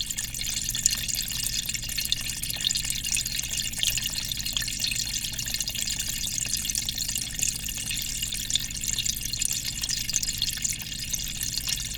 aquarium_small.R.wav